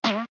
trampoline.ogg